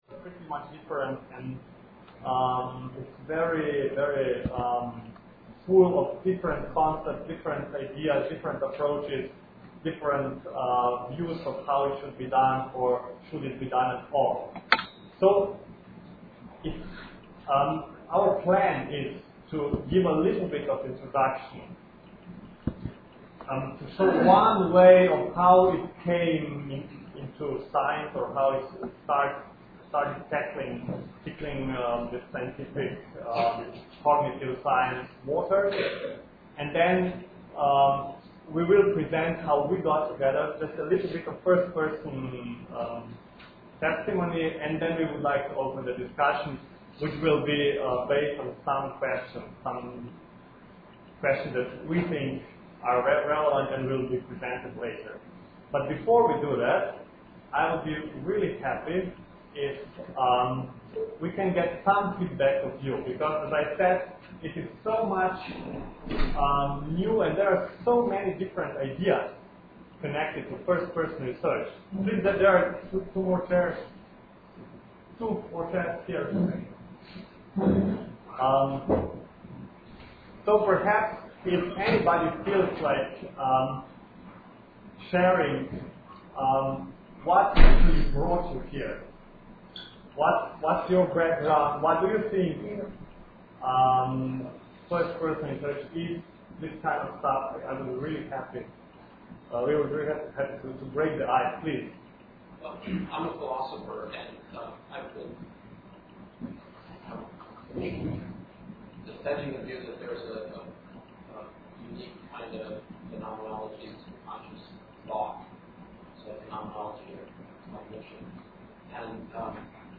Listen to streaming audio of talks given during the retreat.